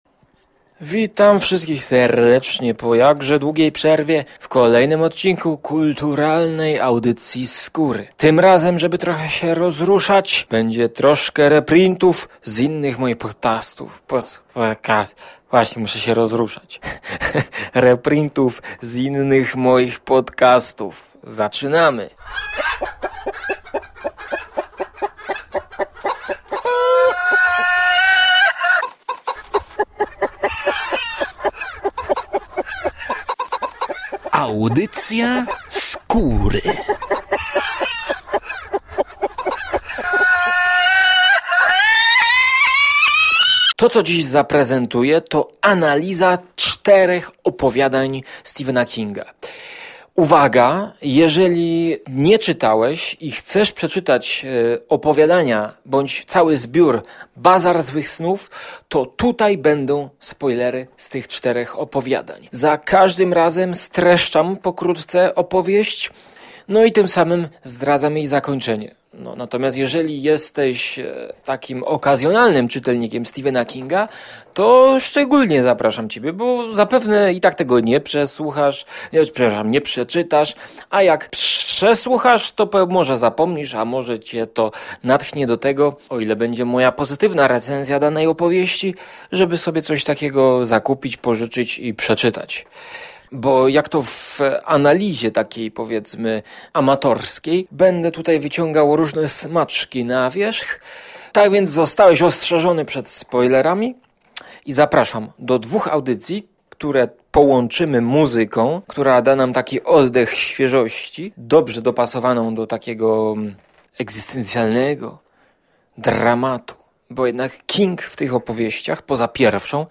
A wszystko okraszam ciekawą WSPÓŁCZESNĄ muzyką elektroniczną.